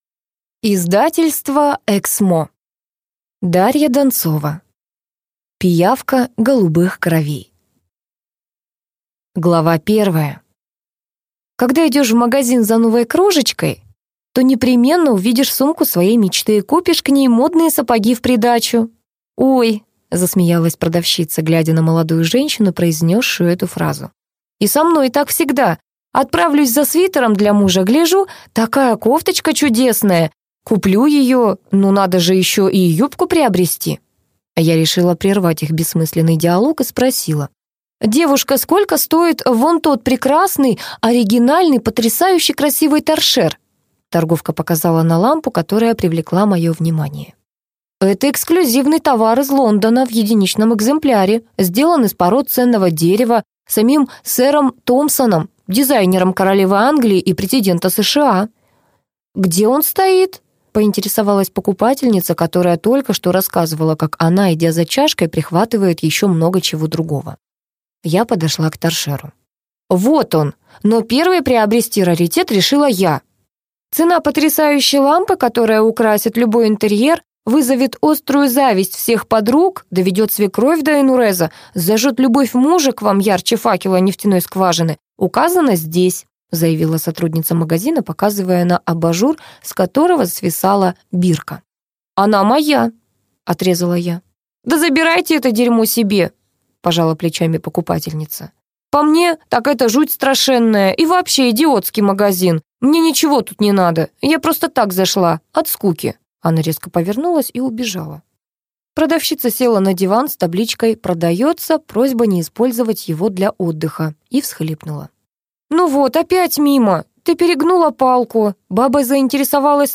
Аудиокнига Пиявка голубых кровей - купить, скачать и слушать онлайн | КнигоПоиск